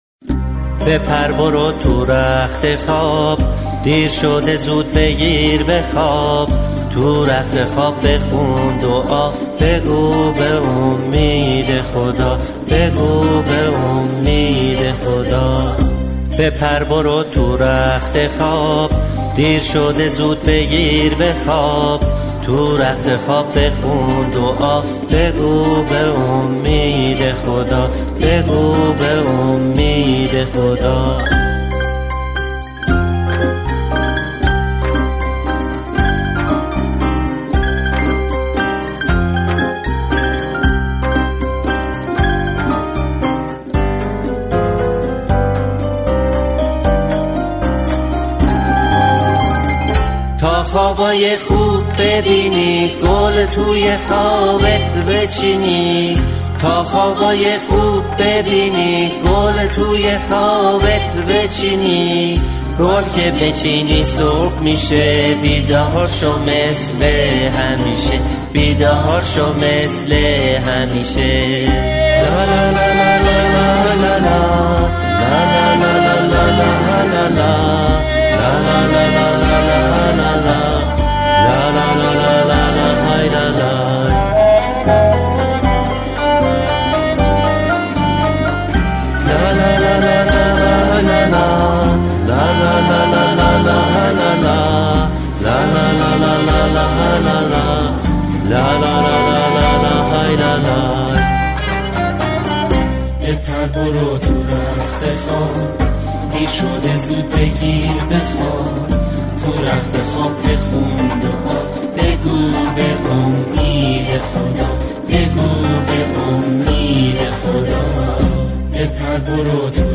آهنگ لالایی